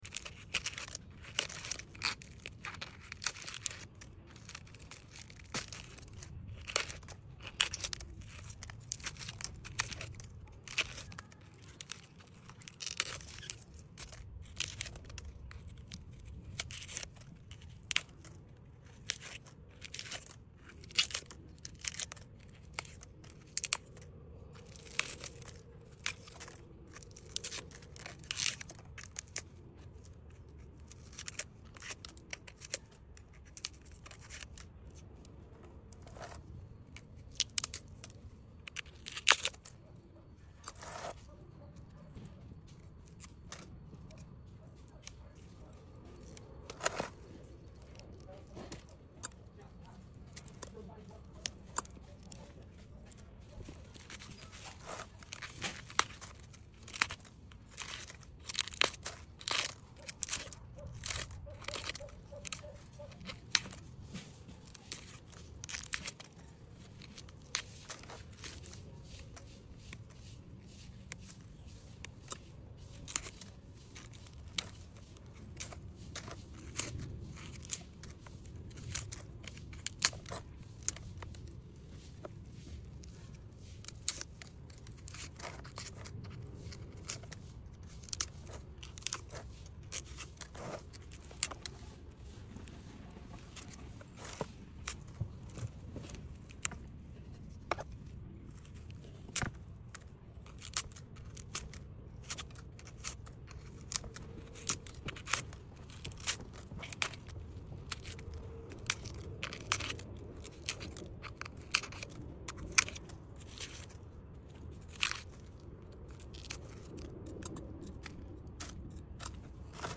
Settle in for an extended ASMR session as I peel away layers of dry succulent leaves. Enjoy every satisfying snap and crisp sound for pure relaxation and plant care bliss.